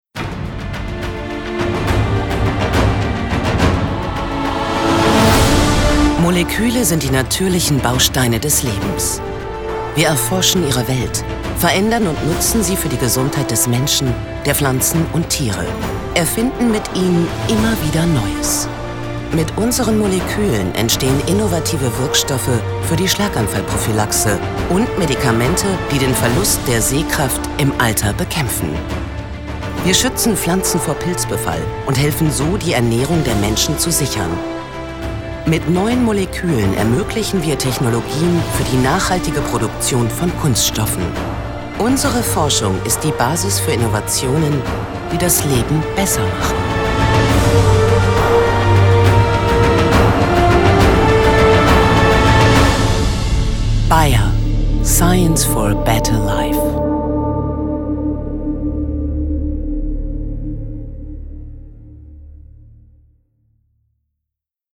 Warme, angenehme Stimme.
Strahlt viel Ruhe aus.
deutsch
Sprechprobe: eLearning (Muttersprache):
female voice over artist